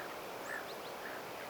hippiäisen ääni
hippiaisen_aani.mp3